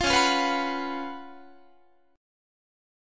C#11 Chord (page 3)
Listen to C#11 strummed